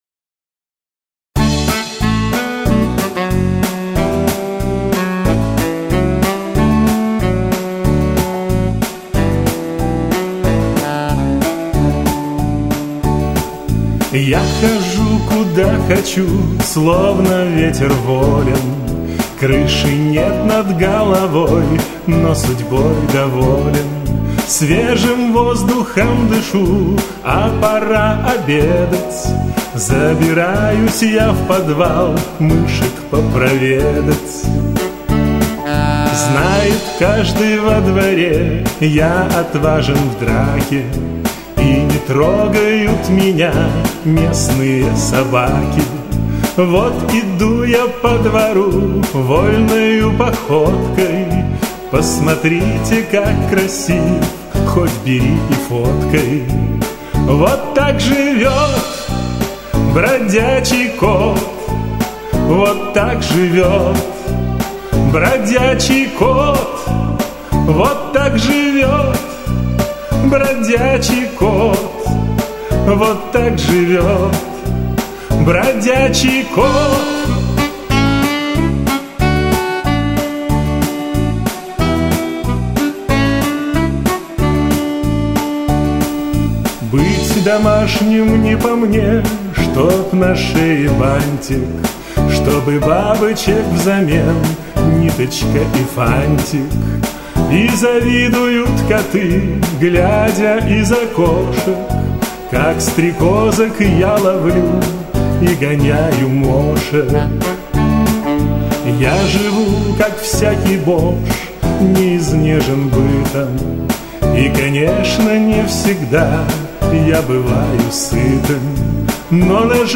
стихи - это песенка))))